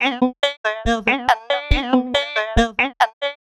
Index of /90_sSampleCDs/Sample Magic - Transmission-X/Transmission-X/transx loops - 140bpm